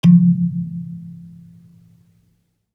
kalimba_bass-F2-pp.wav